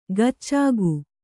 ♪ gaccāgu